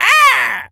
Animal_Impersonations
crow_raven_squawk_07.wav